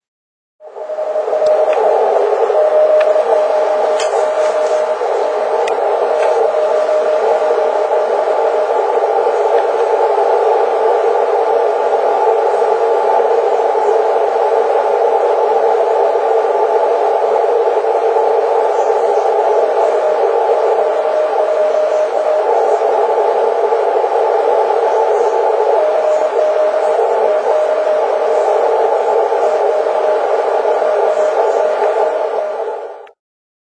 ここではターゲットがしっかり聞こえた。
しかも強いし、だれも呼んでない。